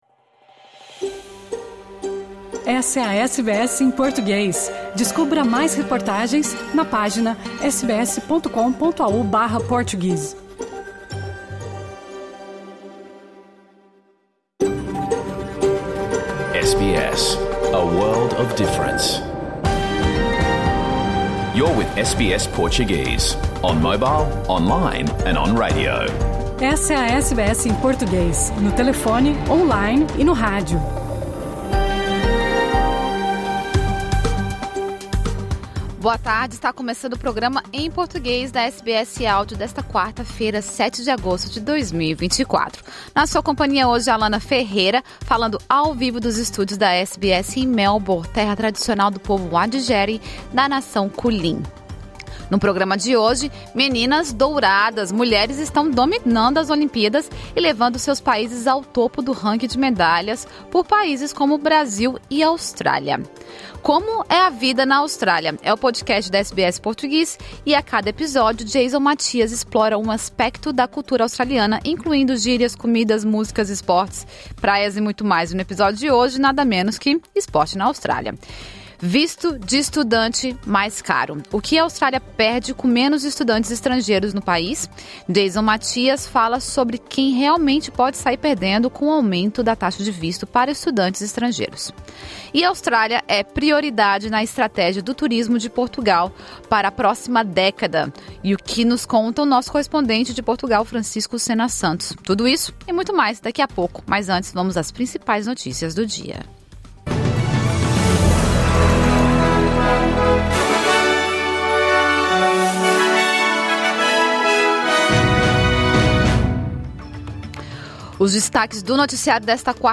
O programa ao vivo que foi ao ar hoje pela SBS na Austrália. Mulheres estão dominando as Olimpíadas e levando seus países ao topo no ranking de medalhas.